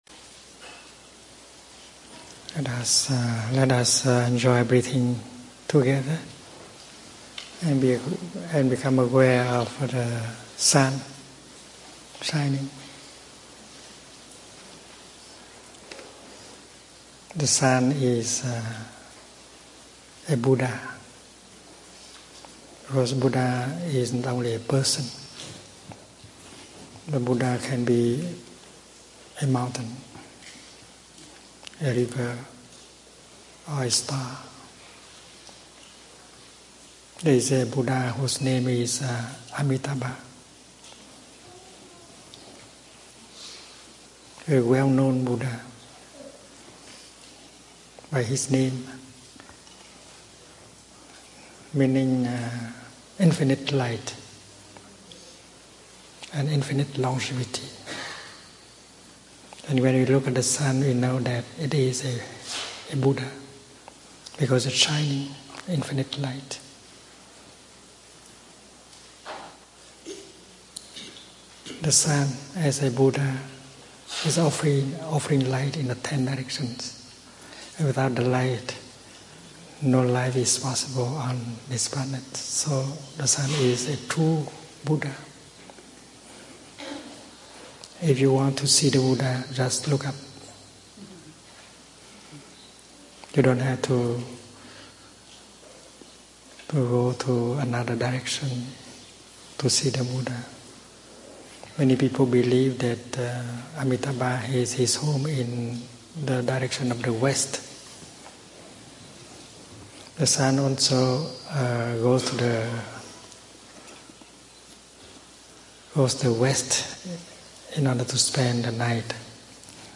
Today we have a session of questions and answers.
We begin with the children, then teens, and finally the adults. 1.